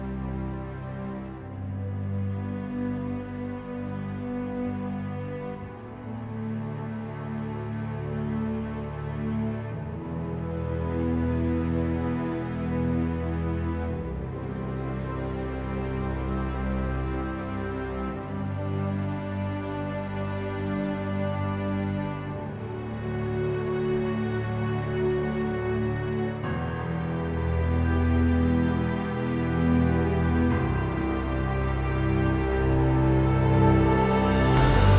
Soundtrack
Жанр: Соундтрэки